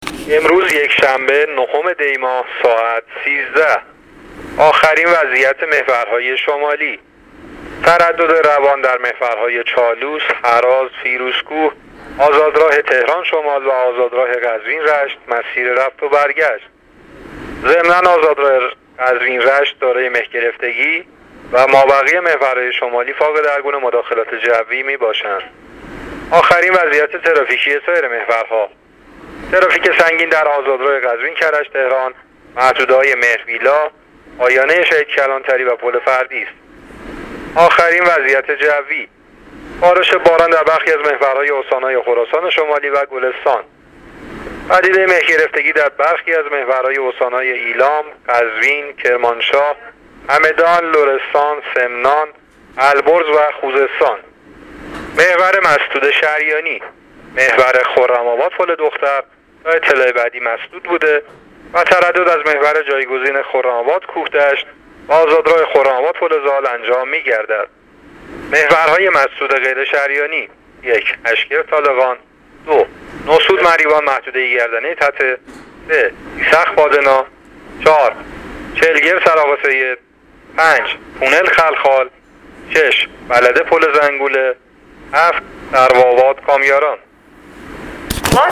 گزارش رادیو اینترنتی از آخرین وضعیت ترافیکی جاده‌ها تا ساعت ۱۳ نهم دی؛